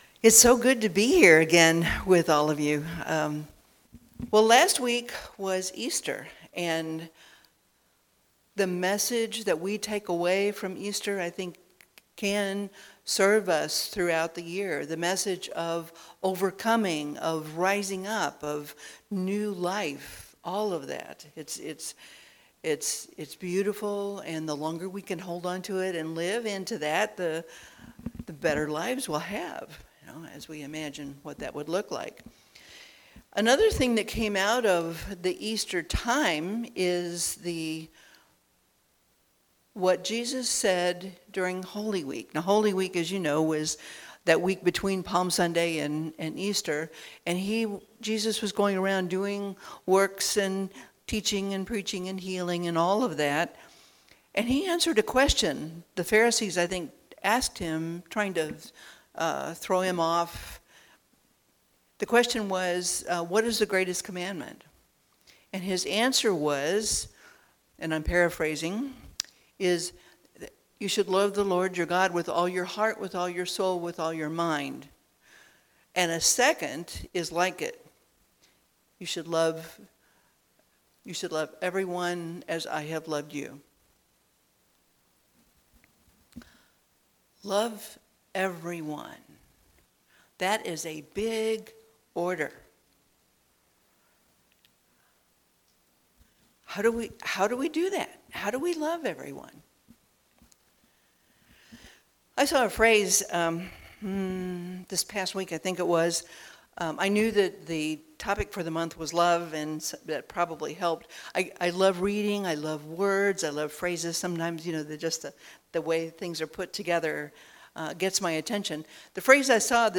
Series: Sermons 2021